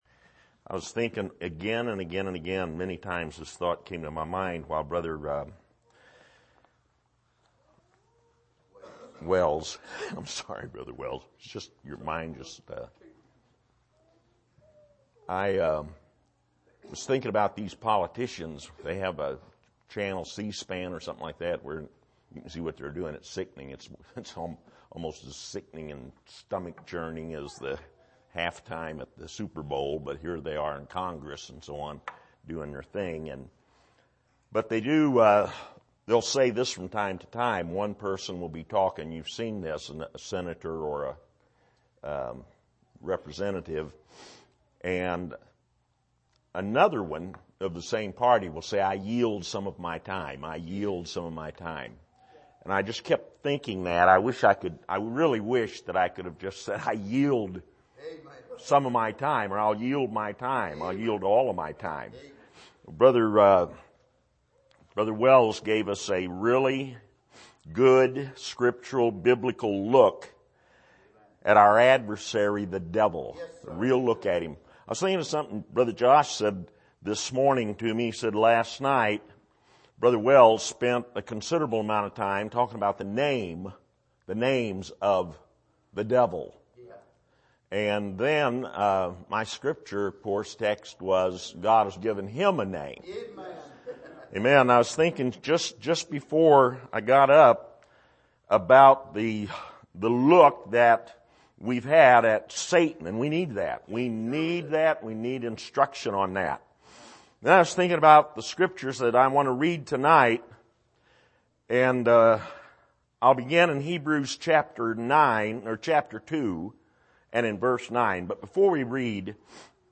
Passage: Hebrews 2:9-10 Service: Bible Conference